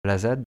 la_zad_prononciation.mp3